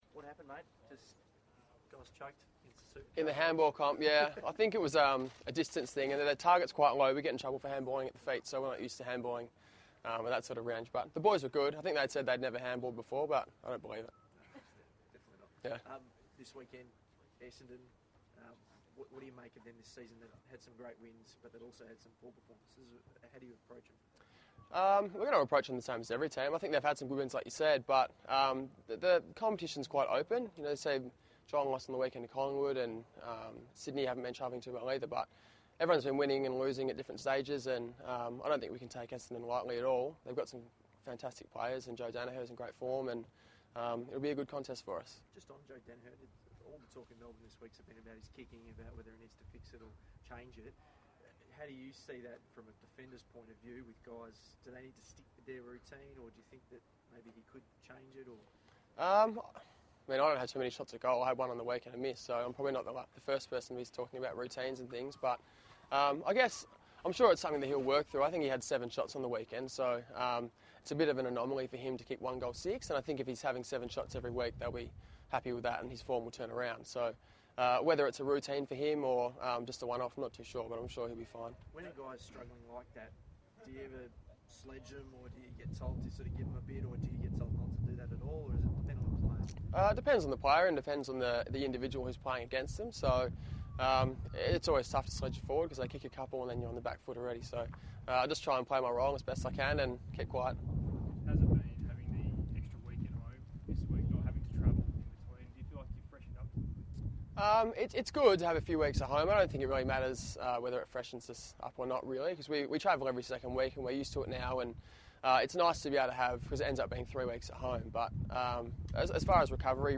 Garrick Ibbotson: Media Conference 4 May 2017